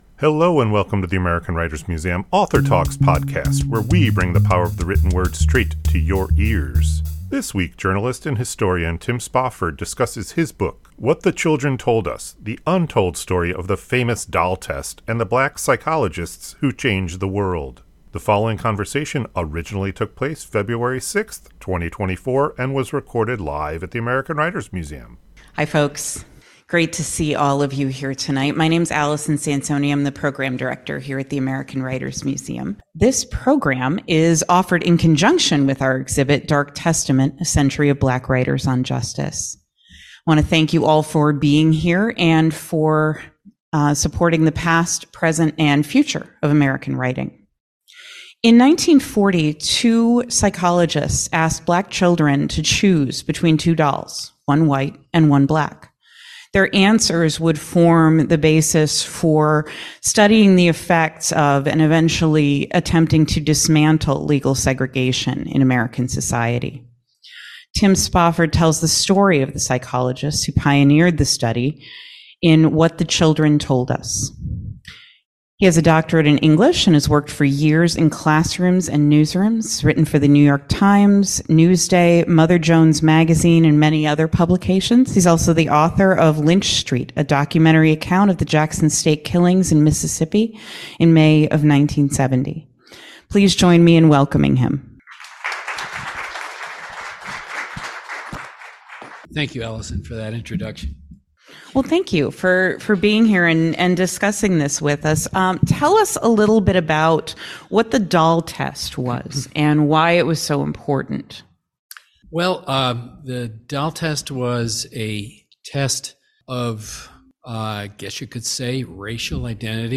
AWM Author Talks Podcast